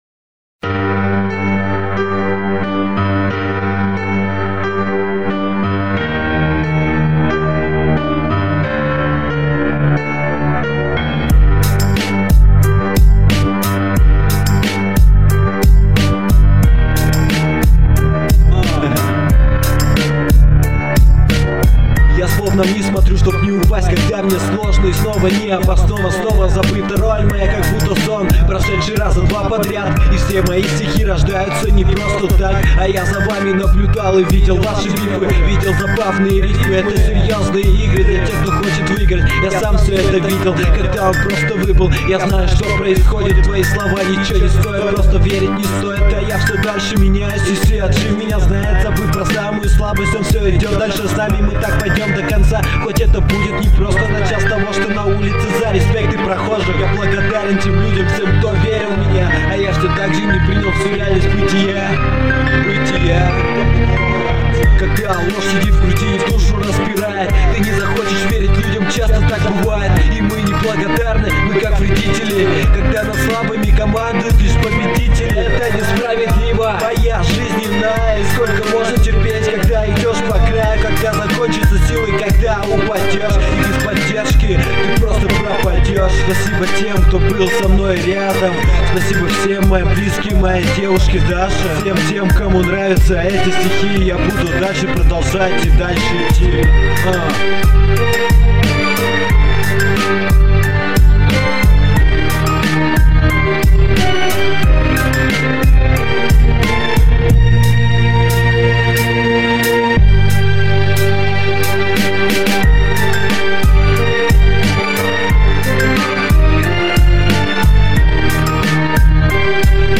Главная » Русский реп, хип-хоп